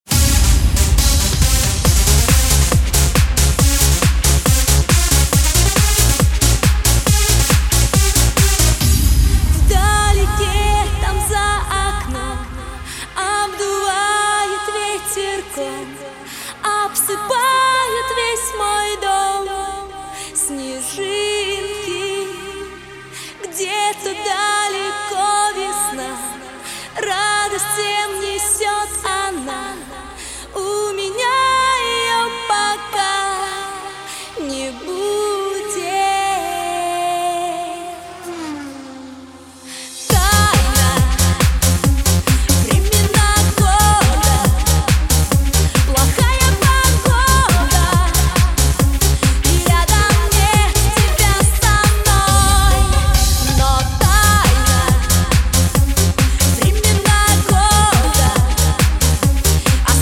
Pop
лучшие образцы танцевальной музыки